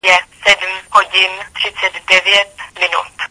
Mluvící hodiny v telefonech Nokia